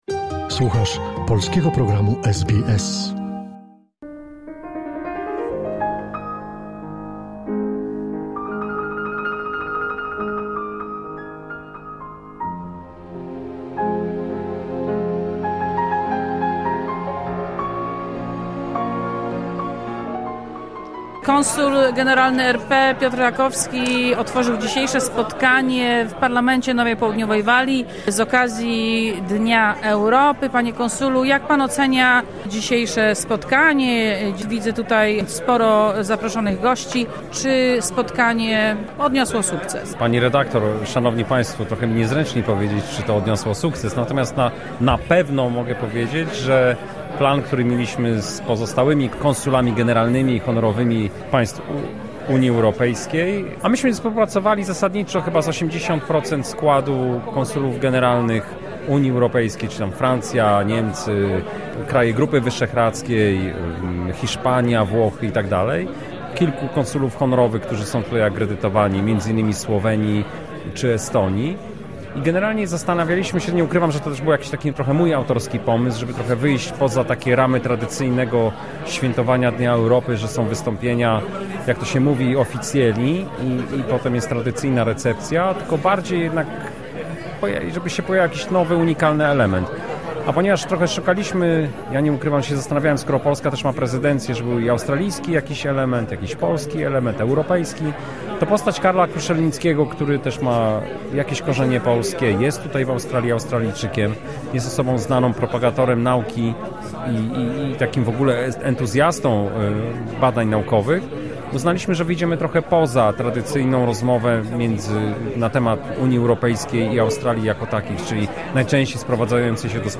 6 maja w siedzibie Parlamentu Nowej Południowej Walii odbyło się spotkanie z okazji Dnia Europy – Europe Day. Na spotkaniu zorganizowanym przez Konsulat RP w Sydney zebrali się lokalni australijscy politycy, w tym przewodniczący Izby Legislacyjnej Parlamentu NSW Ben Franklin, przedstawiciele korpusu dyplomatycznego krajów Europy oraz przedstawiciele wiodących lokalnych organizacji. W roli głównego mówcy gościnnie wystąpił znany australijski popularyzator nauki Karl Kruszelnicki.